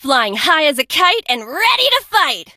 janet_start_vo_04.ogg